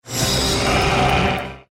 File:Dna mimic roar.mp3
Dna_mimic_roar.mp3